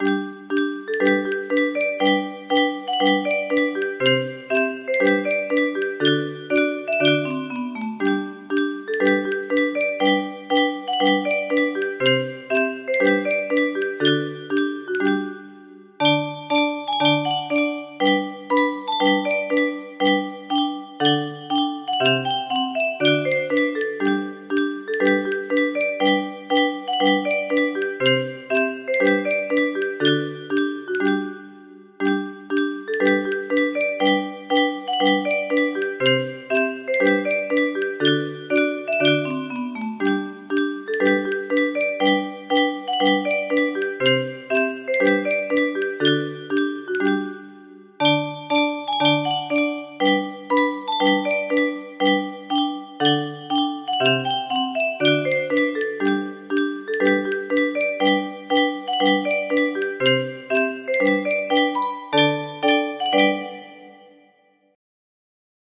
Scotland the Brave music box melody